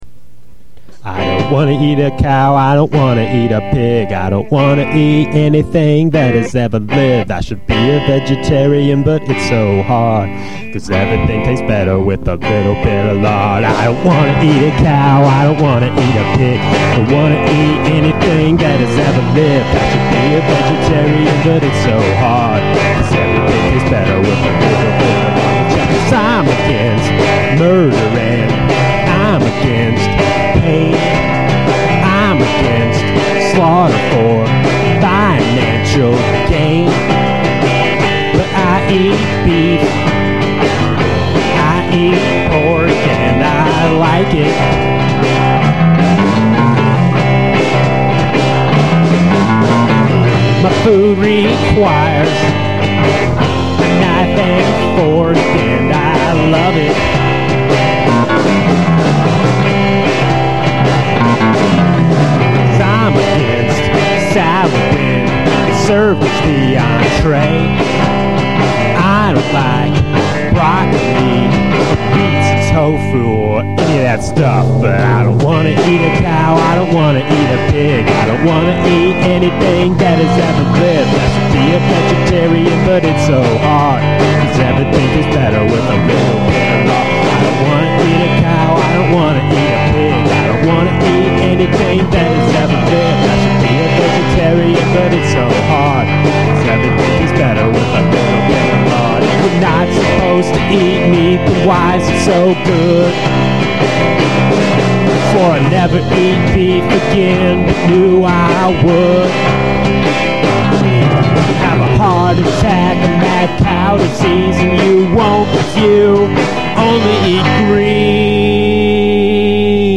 From a St. Louis basement comes a lo-fi gem.